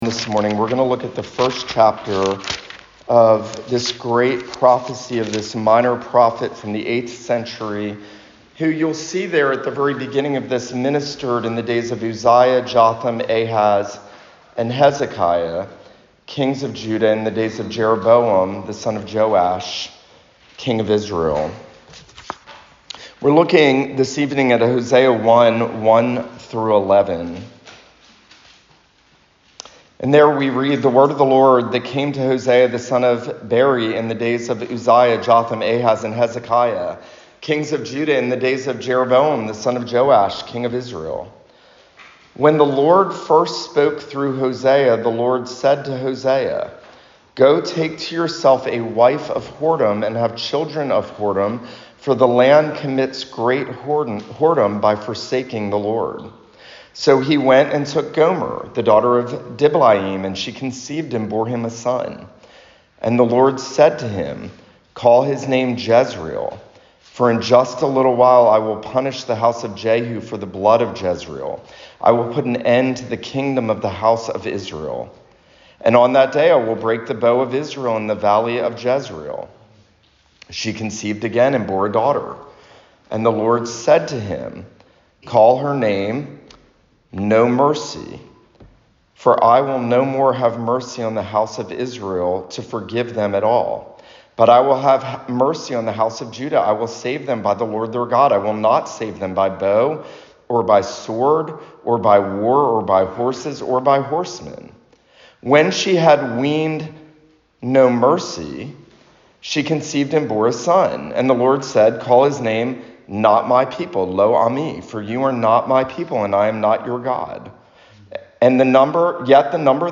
Hosea Sermon Series